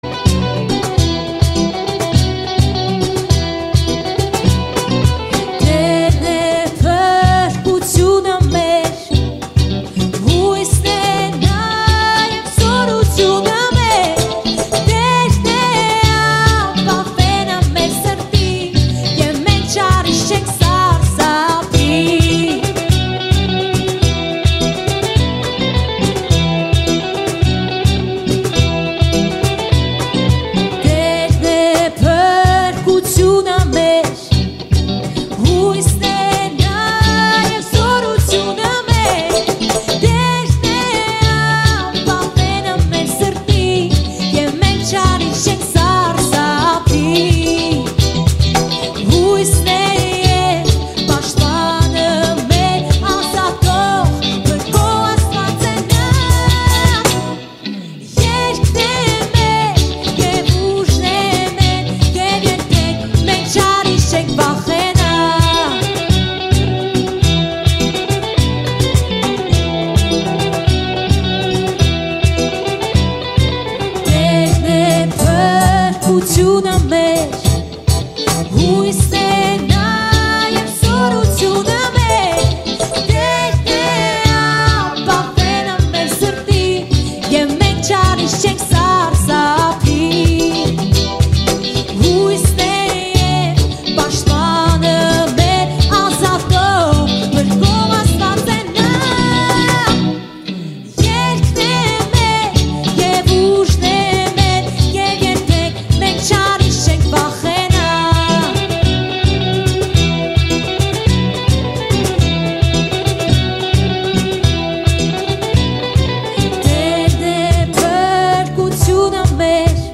73 просмотра 159 прослушиваний 2 скачивания BPM: 90